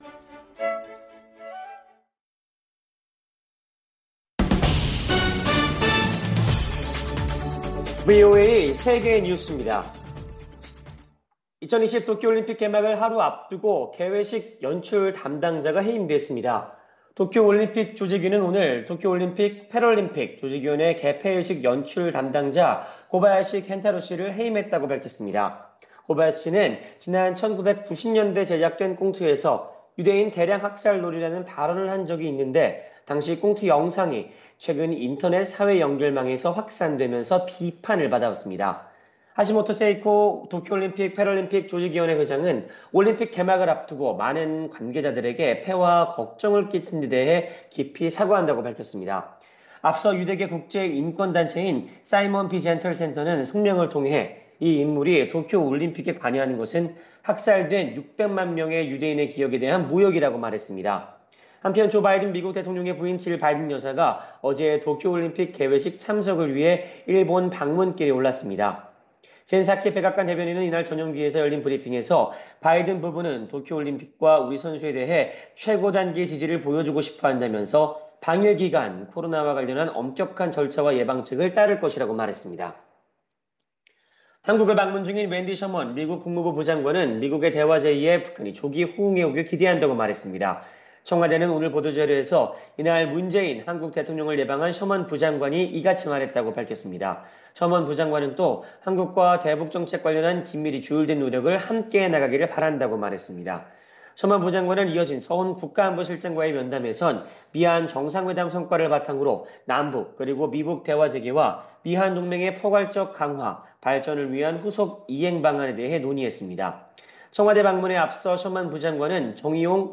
생방송 여기는 워싱턴입니다 저녁
세계 뉴스와 함께 미국의 모든 것을 소개하는 '생방송 여기는 워싱턴입니다', 저녁 방송입니다.